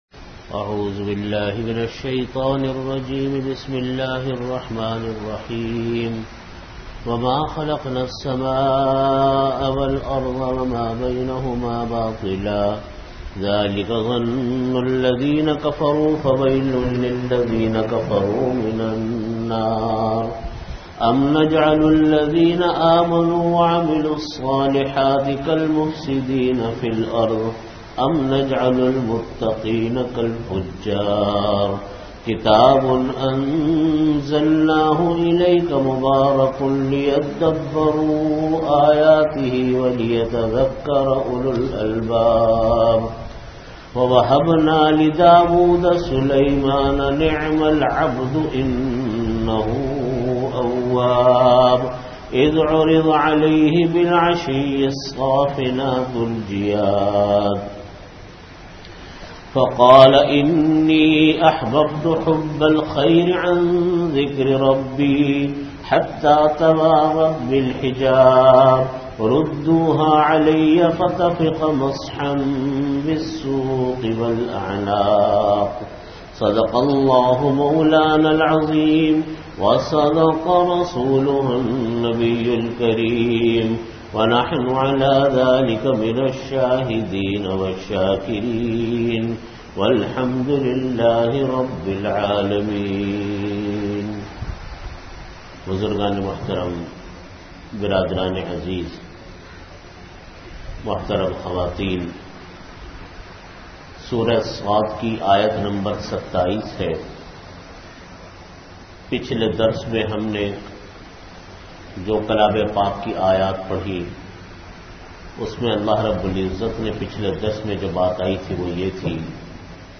Audio Category: Tafseer
42min Time: After Asar Prayer Venue: Jamia Masjid Bait-ul-Mukkaram, Karachi